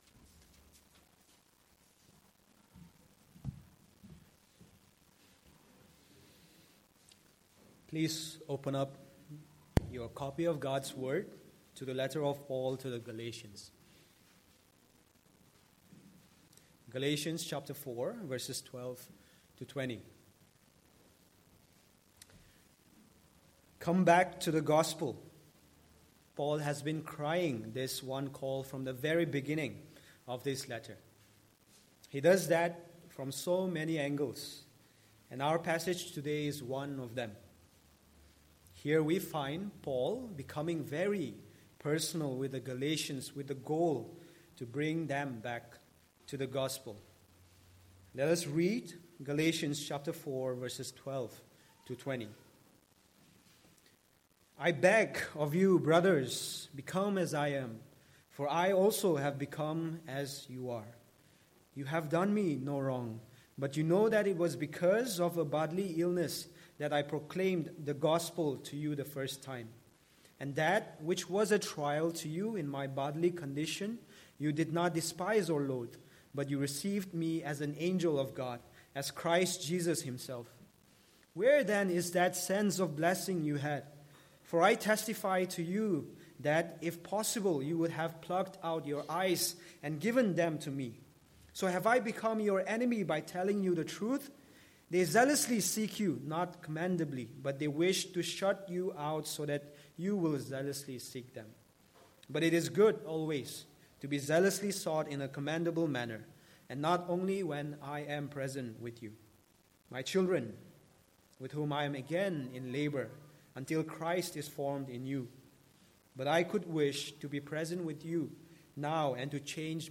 Passage: Galatians 4:12-20 Service Type: Sunday Morning